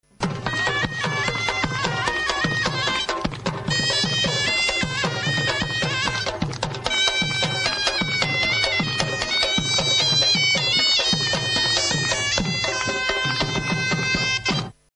And there was no one to play the traditional melody on the raspy, flute-like surnai.
"Atan-i-Mili" performed with surnai